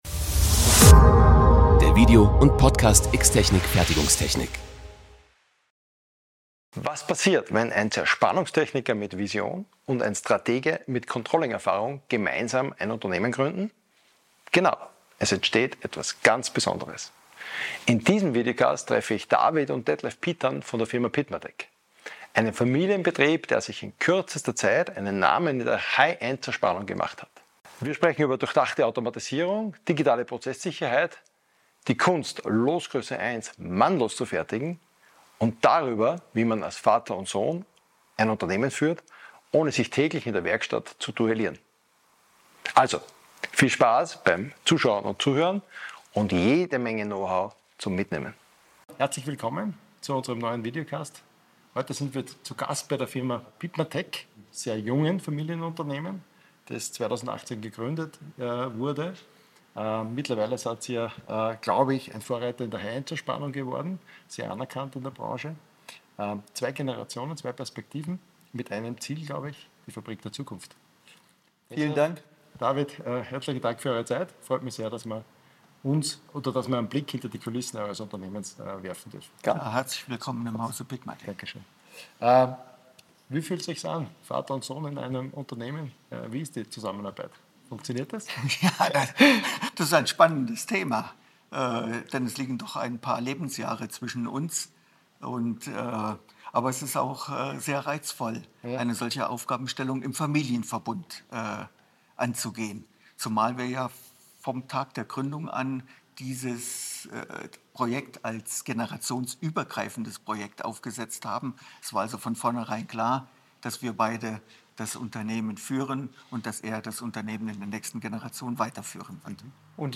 Ein spannendes Gespräch über die Zukunft der Fertigung, wie sie jetzt schon bei PitMaTec Realität ist.